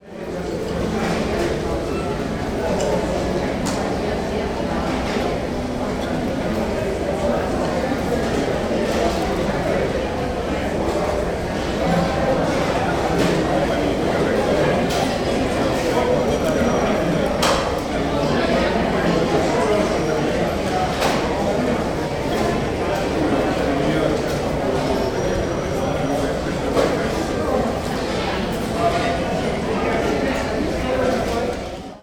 The sounds of people at dinner noisily following talk more winding than the Merced in Happy Isles.
006_foodCourt_lodge.ogg